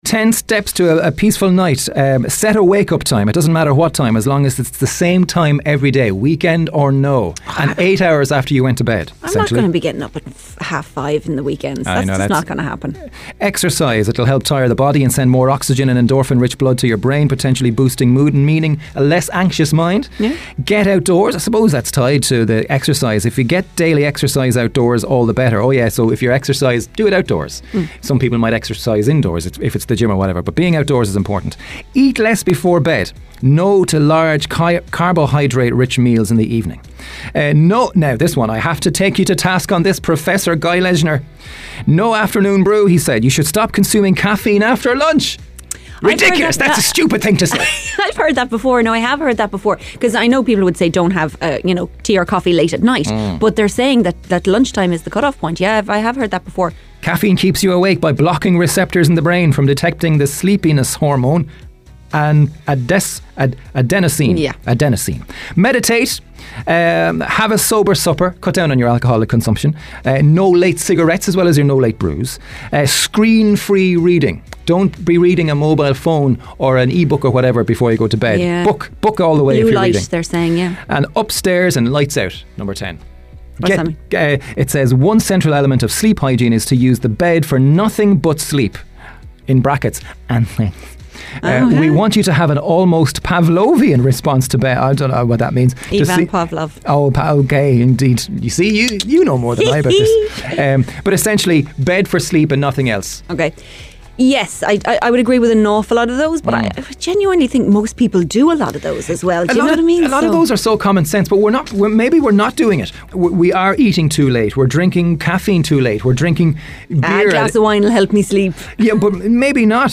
went through the list on this morning’s Big Breakfast Blaa to give their thoughts on whether the list could realistically work.